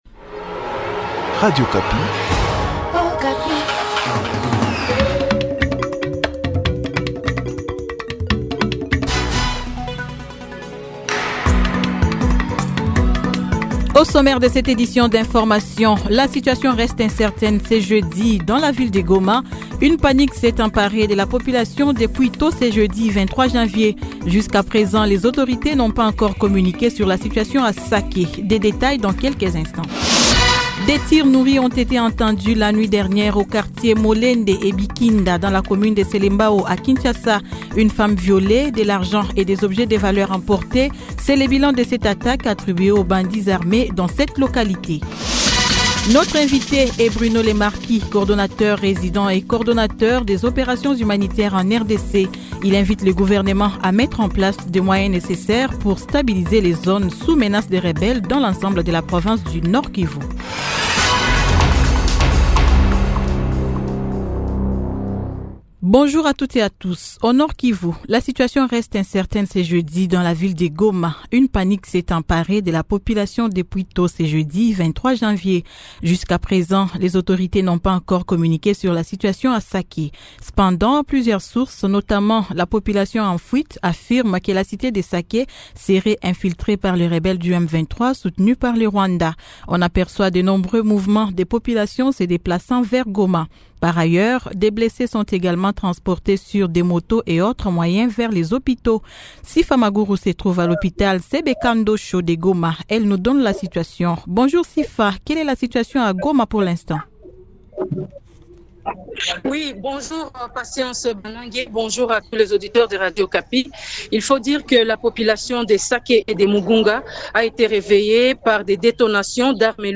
Journal Midi
Goma : Le M23 à Sake, témoignage d'un habitant. Goma : Des nouveaux déplacés qui arrivent dans la ville de Goma.